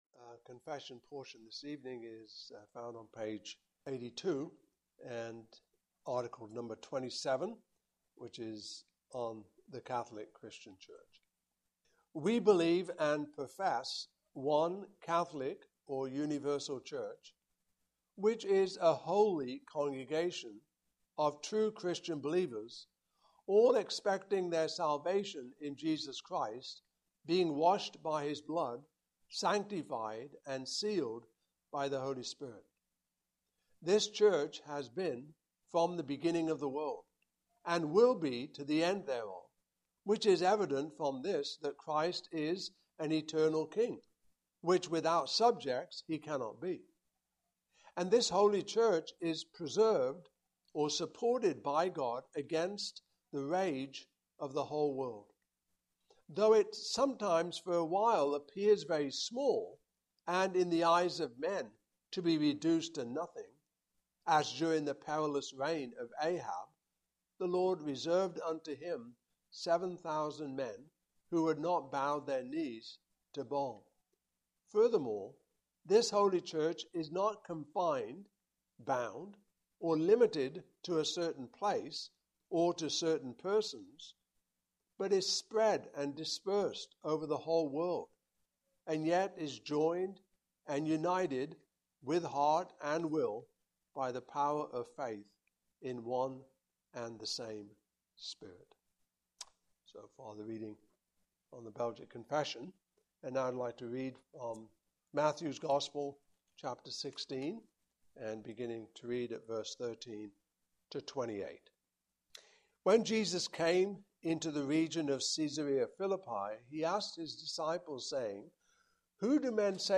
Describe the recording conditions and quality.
Passage: Matthew 16:13-28 Service Type: Evening Service